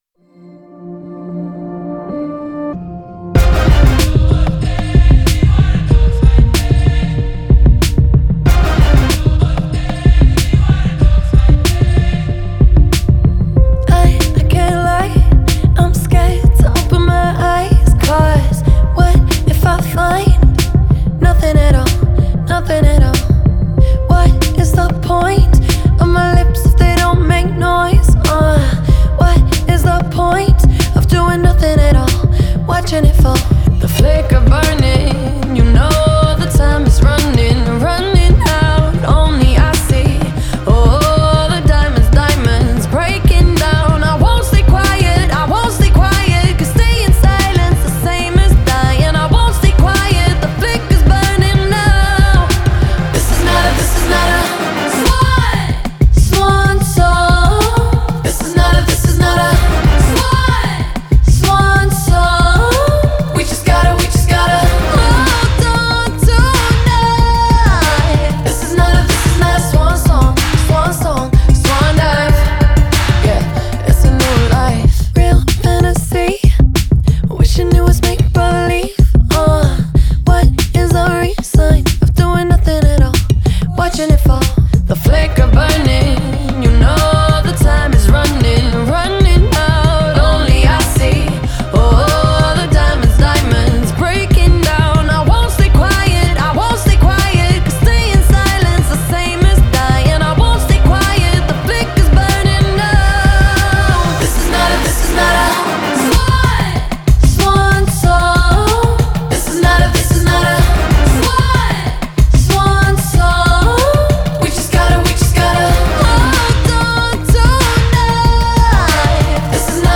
• Жанр: Корейские песни